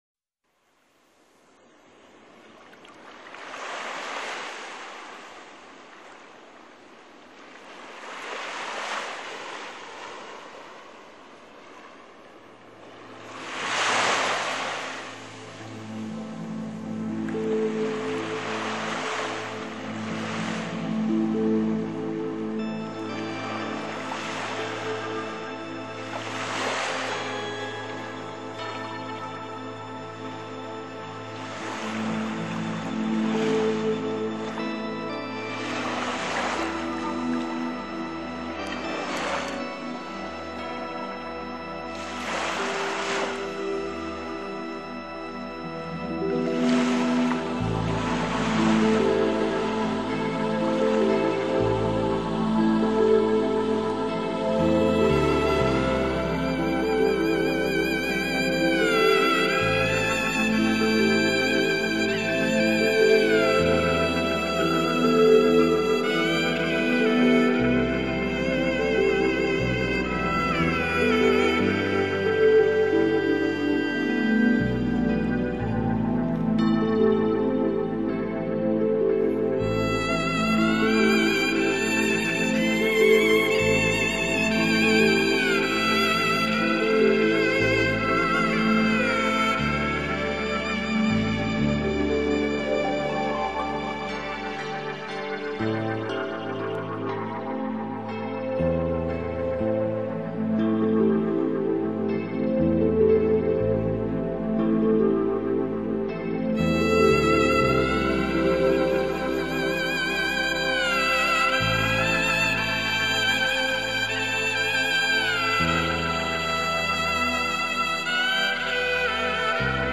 在音乐中融入大自然的声音：水声、虫鸣、鸟叫、海潮声等， 彻底提升音乐的悠然意境。